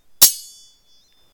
sword_clash.6.ogg